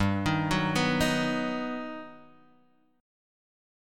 G+M7 chord